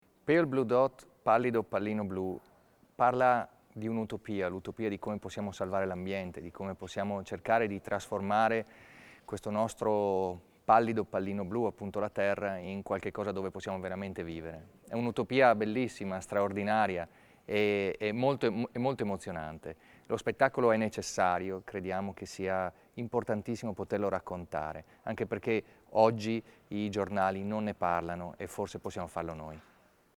In allegato interviste e comunicato stampa -